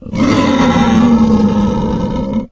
boar_death_3.ogg